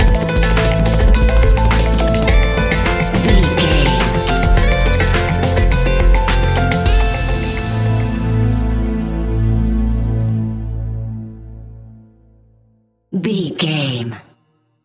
Ionian/Major
A♭
electronic
techno
trance
synths
synthwave